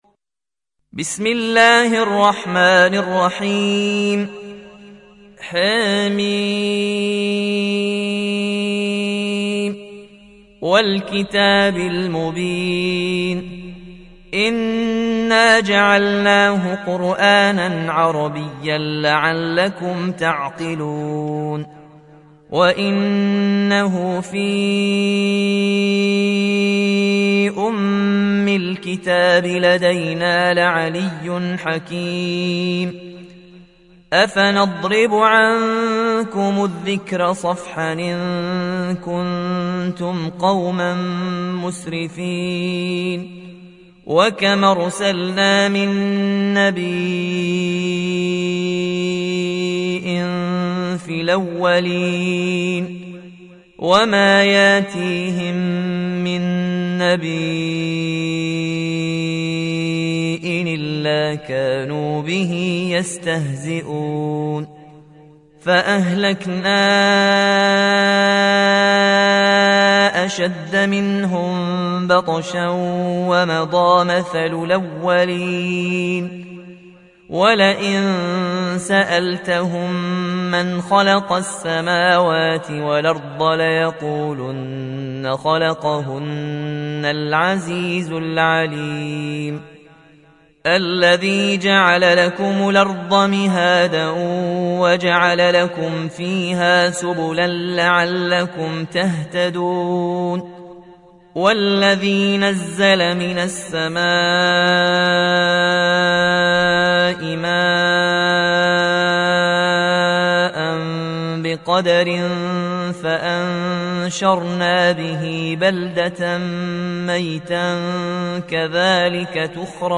Warsh থেকে Nafi